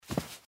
投掷.mp3